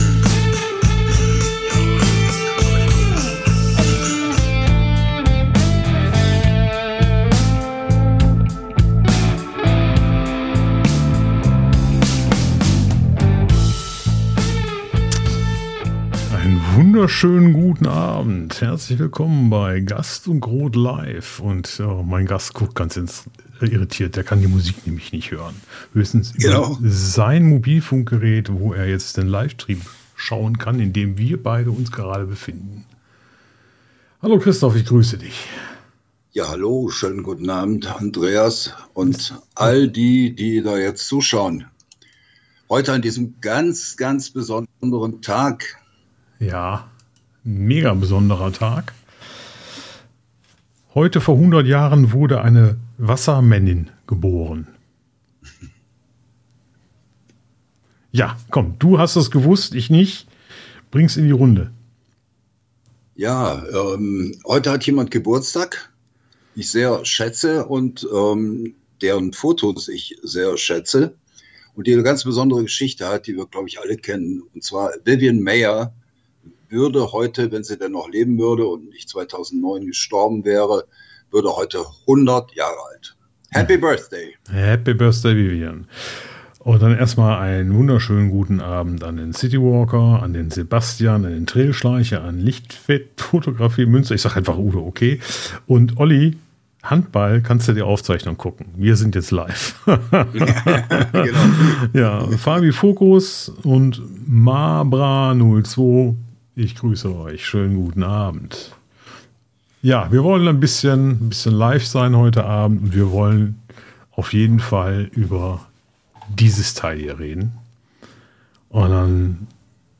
Aufzeichnung vom Livestream